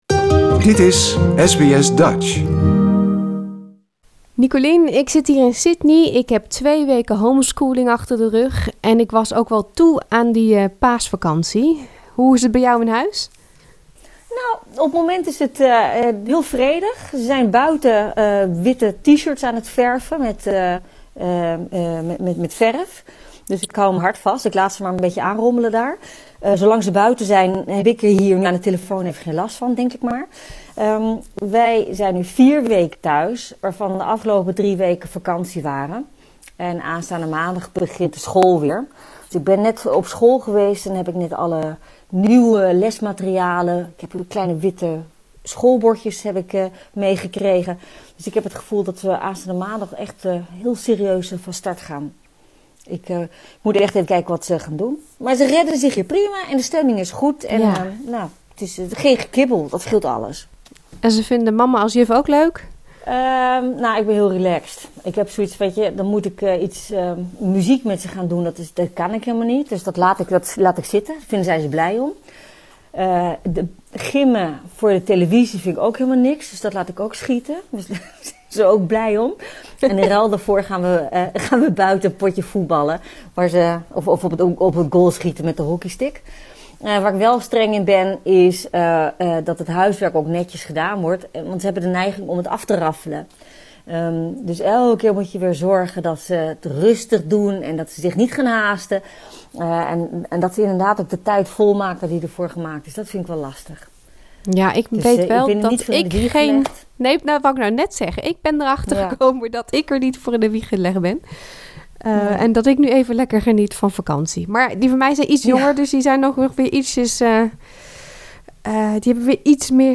Politiekdeskundige en voormalig Tweede Kamerlid Nicolien van Vroonhoven heeft wel een verklaring.